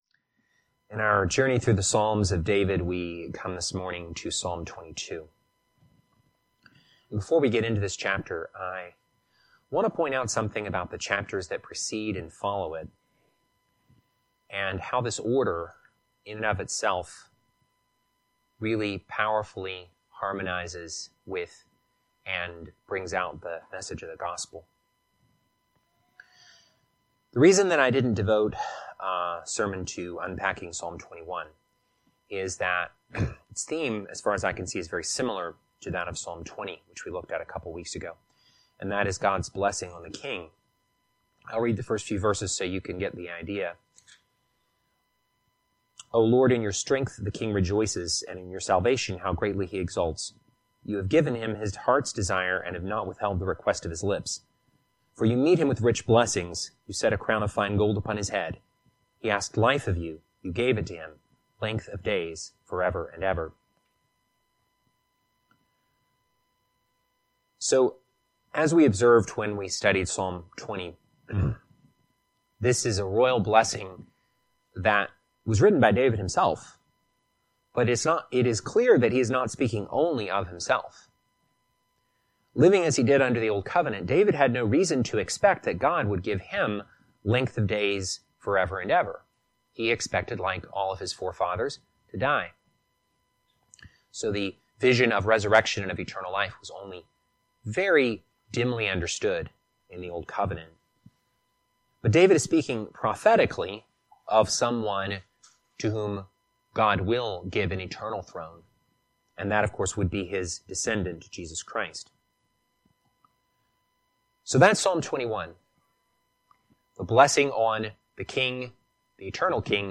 Teaching For December 14, 2025